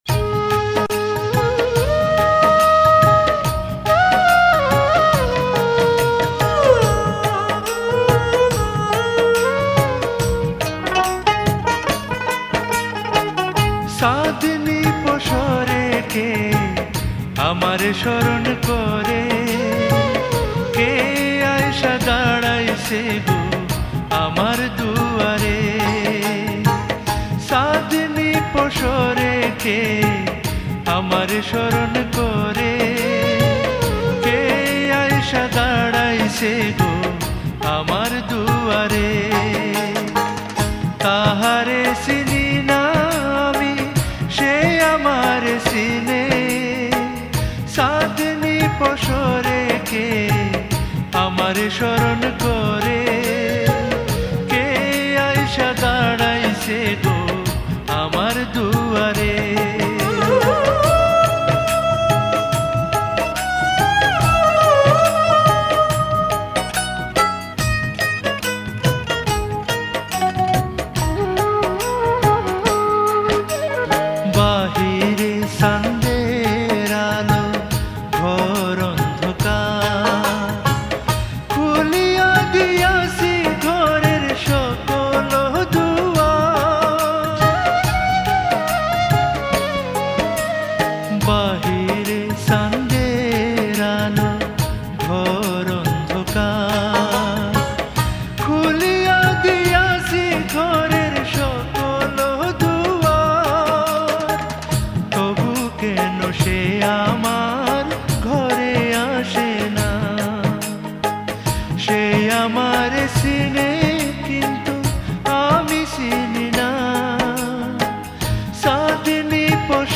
Bangladeshi Movie Song